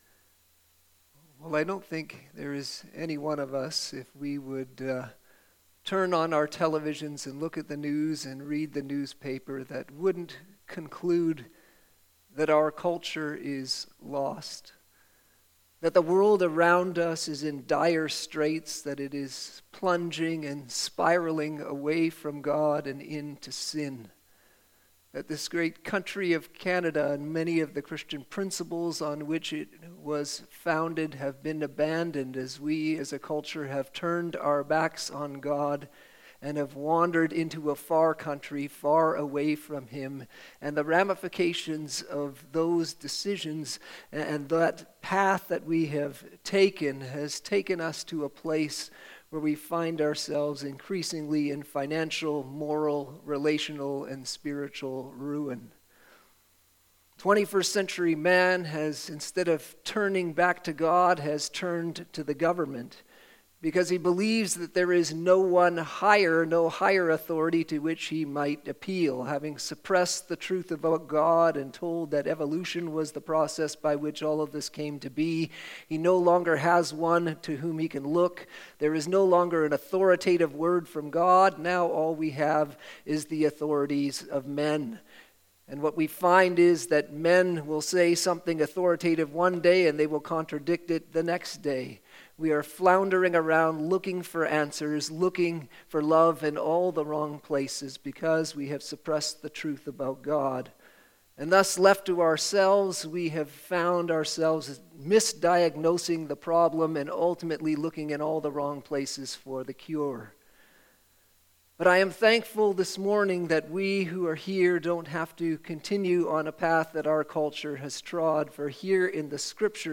Sermons - Christ Community Church